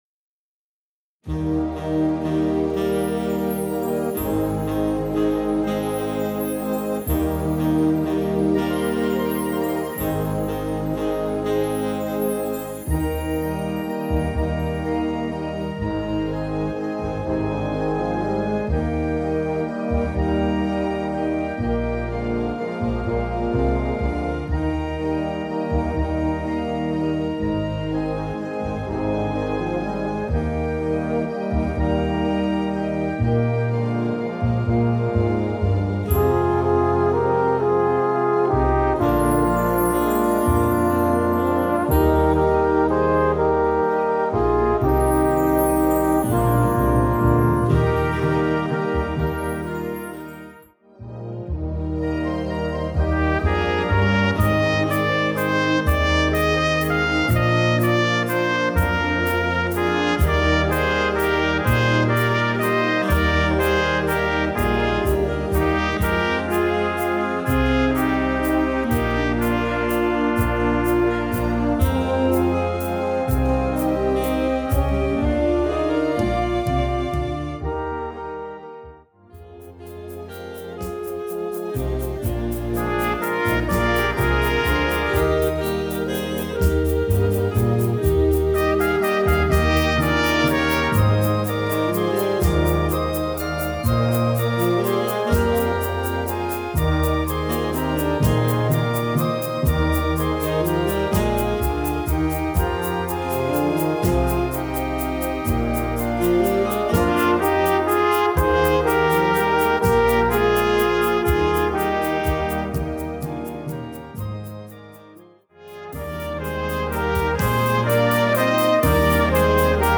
Besetzung: Blasorchester
Tonart: Eb-Dur